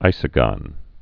(īsə-gŏn)